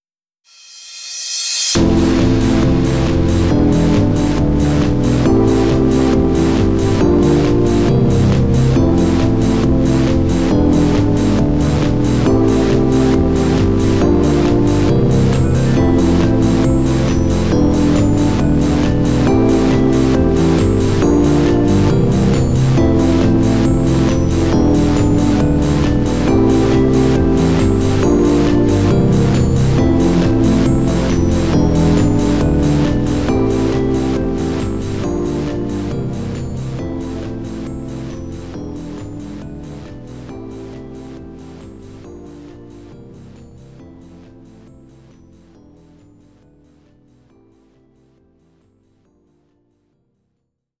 Off vocal